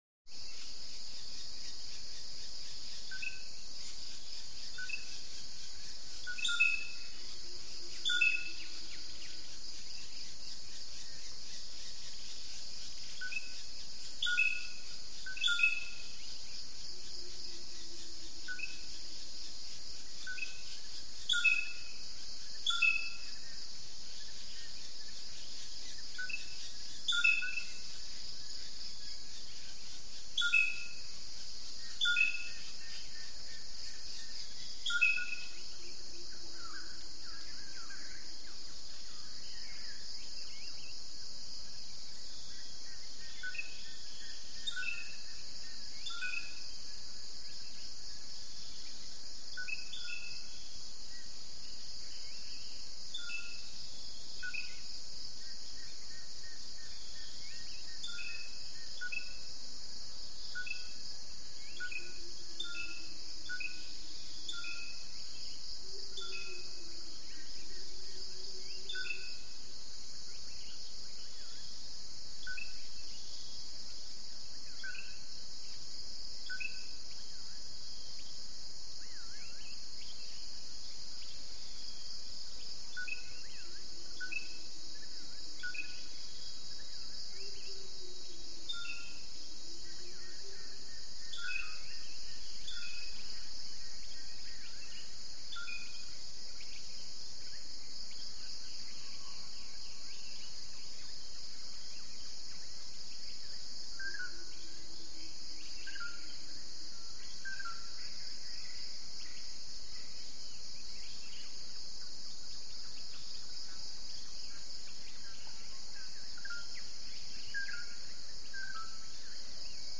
Звуки рассвета
На этой странице собраны звуки рассвета — нежные трели птиц, шелест листвы и другие утренние мотивы.
Рассвет на тайском острове Самуи